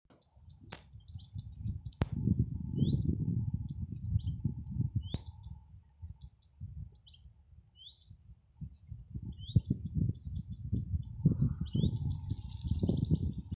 Common Redstart, Phoenicurus phoenicurus
StatusAgitated behaviour or anxiety calls from adults